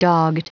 Prononciation du mot dogged en anglais (fichier audio)
Prononciation du mot : dogged